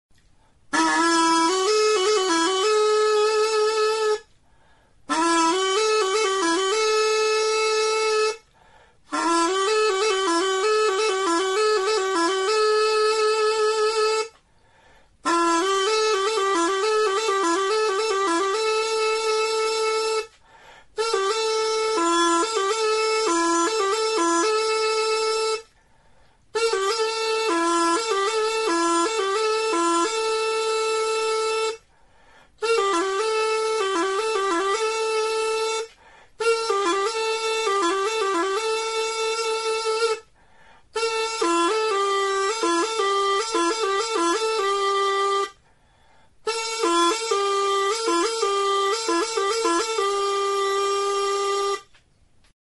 Aérophones -> Anches -> Double (hautbois)
Enregistré avec cet instrument de musique.
Gari zuztarraren ordez, edateko plastikozko lastotxo batekin egindako turuta. Tonu aldaketarako 2 zulo ditu.